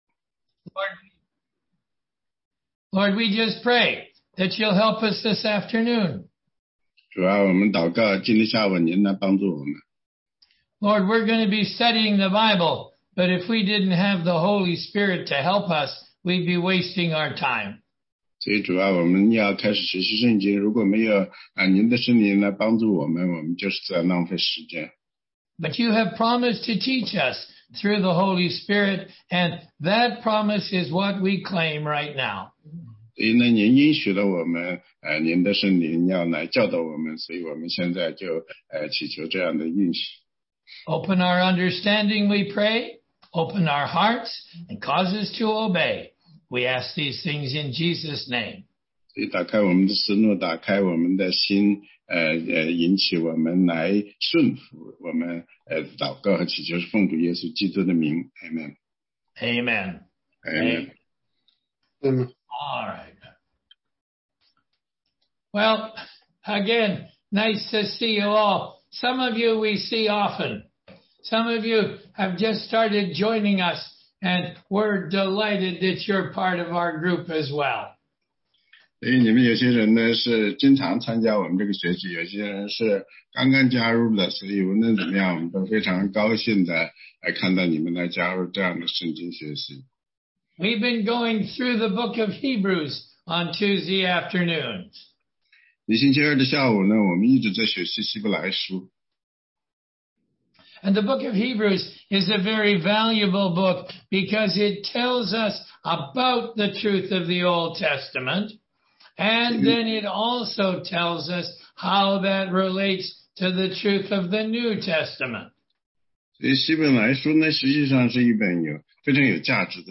答疑课程